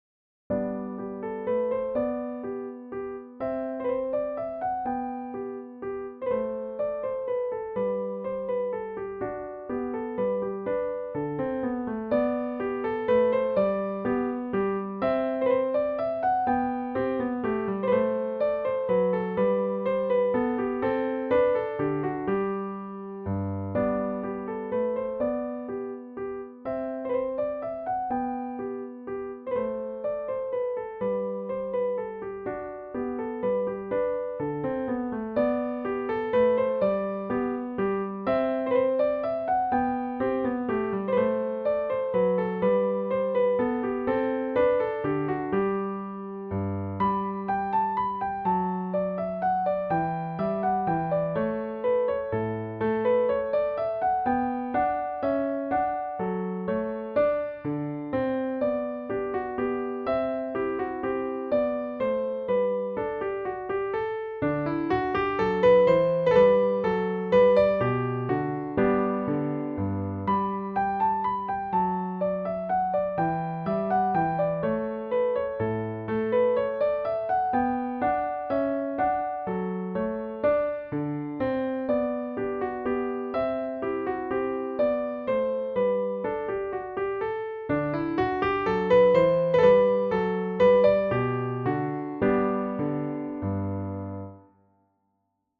for piano as an mp3 file or play it below: